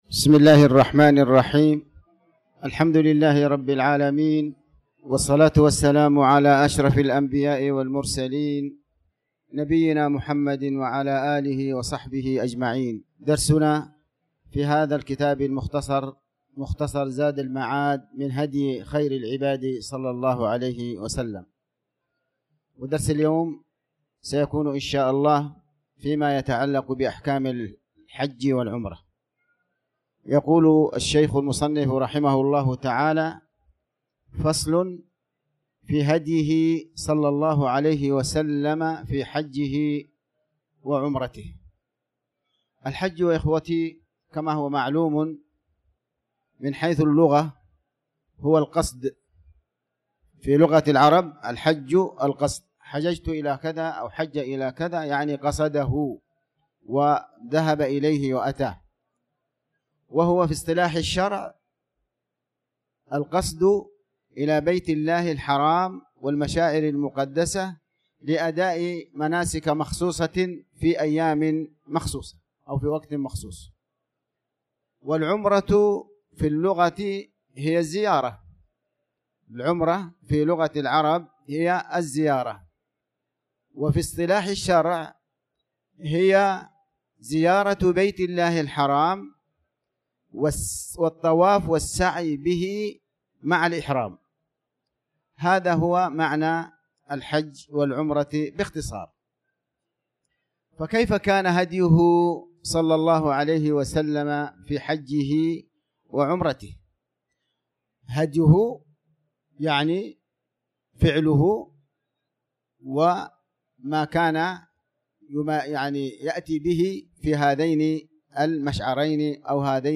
تاريخ النشر ١٢ شعبان ١٤٤٠ هـ المكان: المسجد الحرام الشيخ